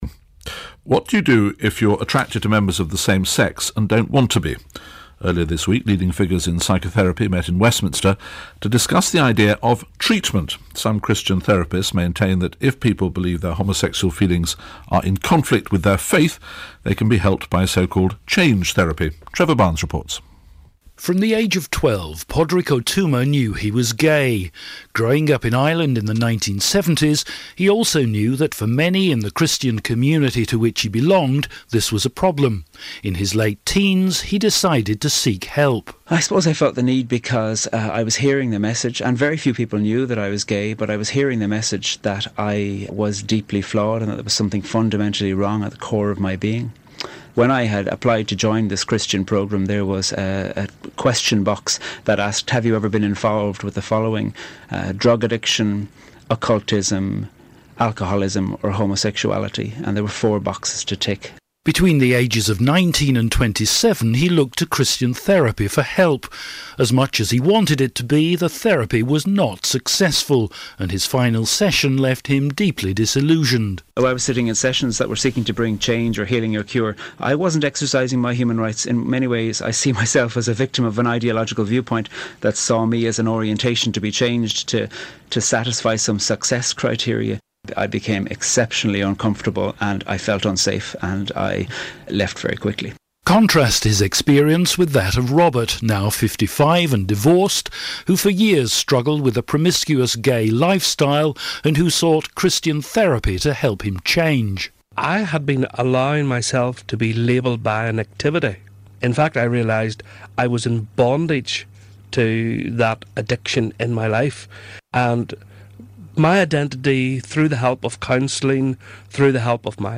Report on sexual orientation therapy